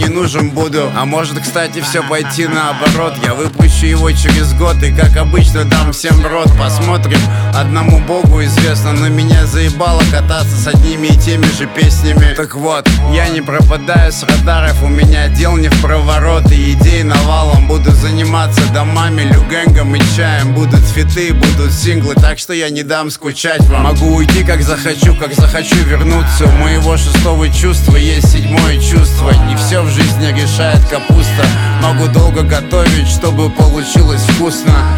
Hip-Hop Rap